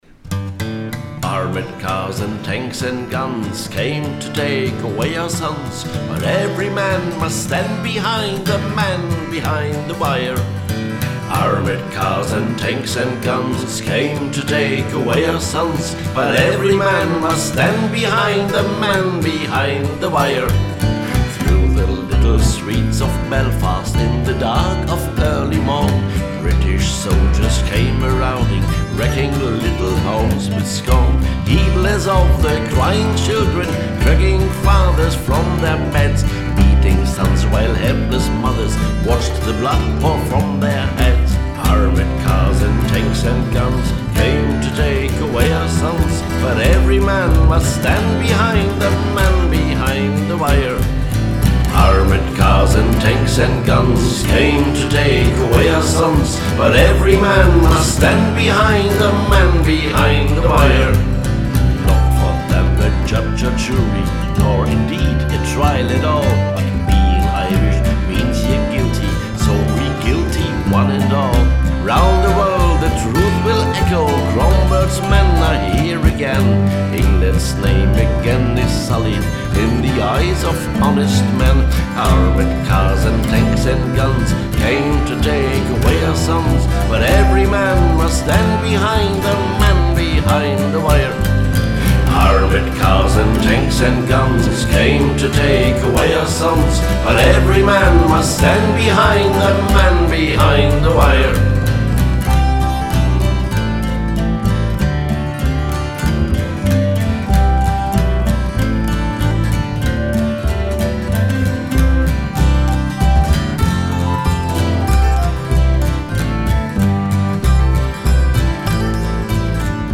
Rebelsong.
Die Melodie ist eher „schmissig“, der Text aber sehr ernst.